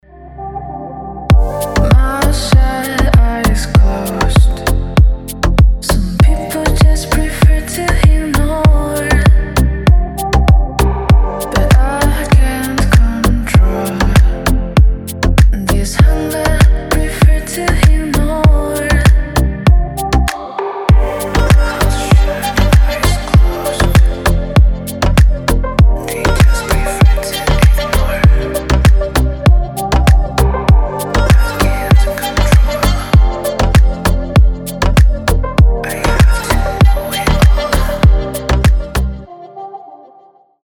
• Качество: 320, Stereo
красивые
deep house
спокойные
чувственные